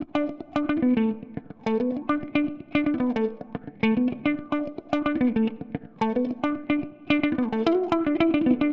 35 Guitar PT1.wav